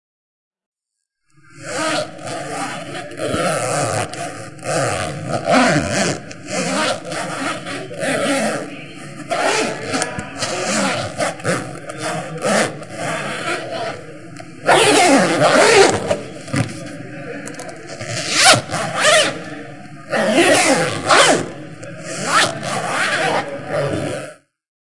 bag noise with zip
描述：opening the zip of a bag, searching for something and closing the zip again ( a lot of things inside the bag: pill boxes, lip sticks, etc.) there is also the sound of a hookandloop fastener.
标签： zipper bag dig zip search hookandloopfastener
声道立体声